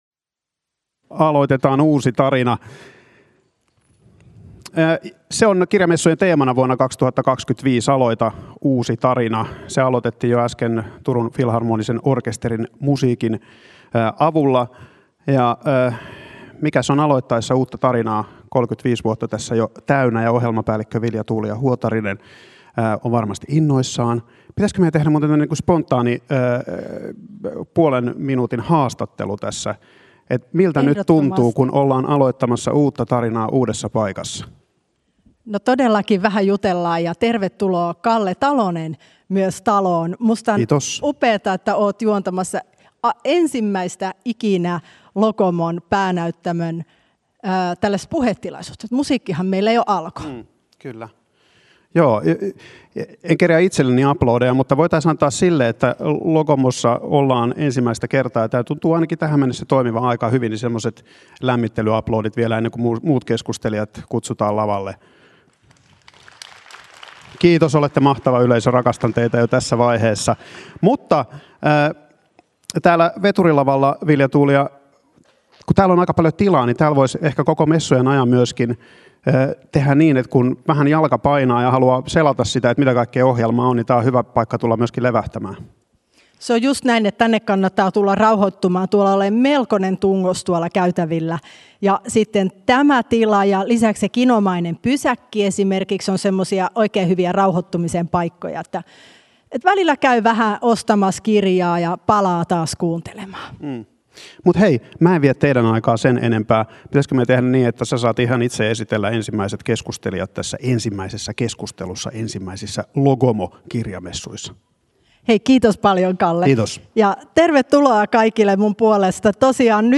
Kirjamessujen konkarit muistelevat värikkäitä messutapahtumia vuosien varrelta. Muistoja, runoa ja musiikkia.